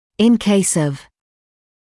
[ɪn keɪs ɔv][ин кейс ов]в случае